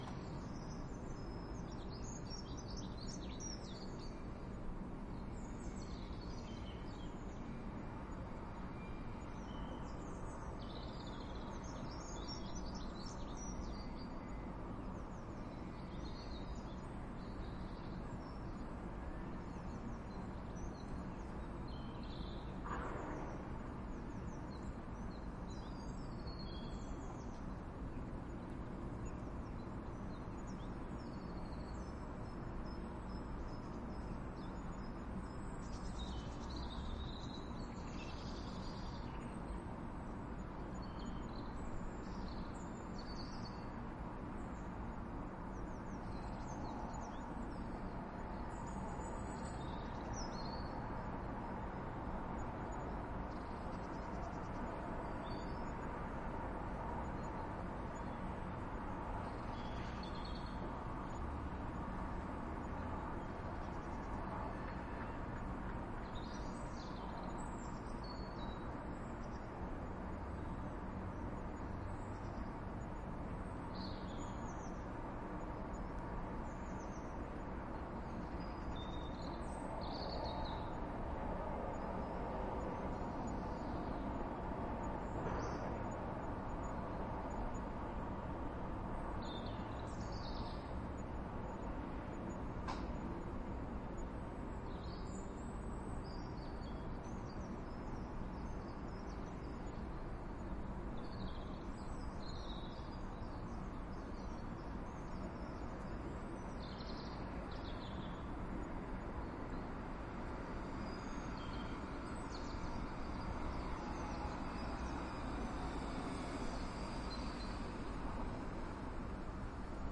描述：轻交通，鸟类，行人，教堂钟，救护车 录音机/麦克风：放大H4n Pro 此声音受许可协议约束。
标签： 鸟类 街道 汉堡 一般的噪音 行人 现场录音 交通 城市 环境
声道立体声